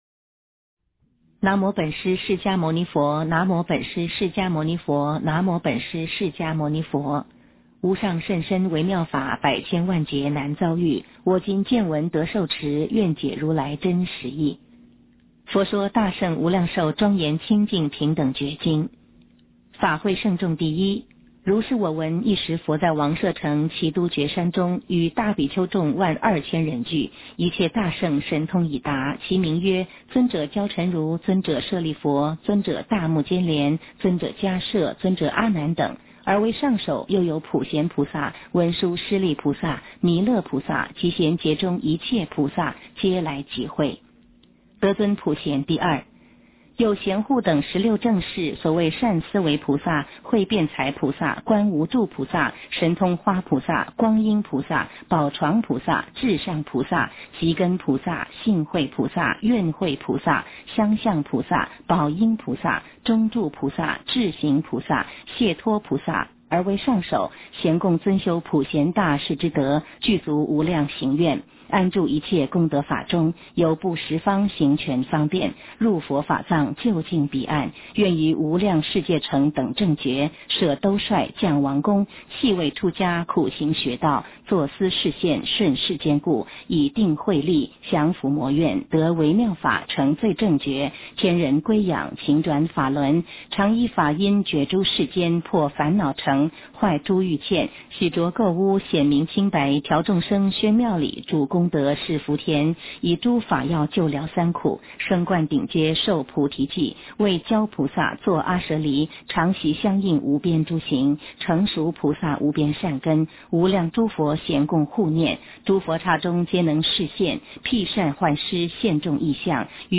无量寿经（女声念诵）